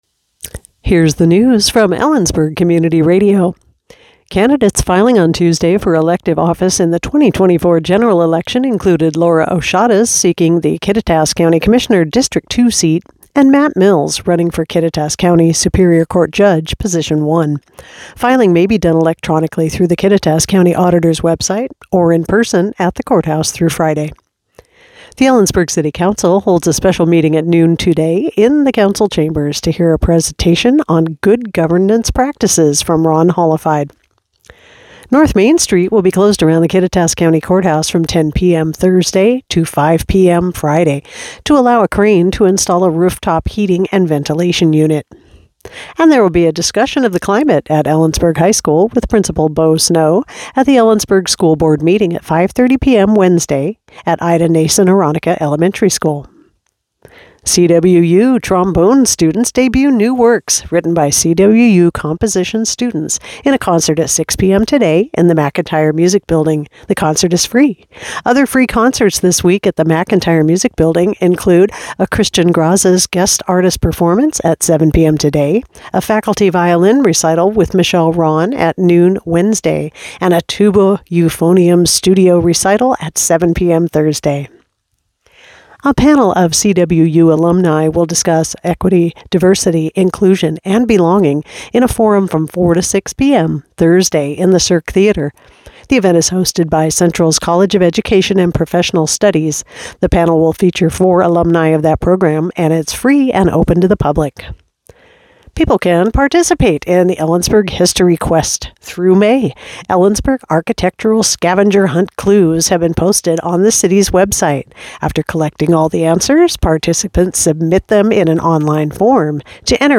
Click here to listen to today's newscast.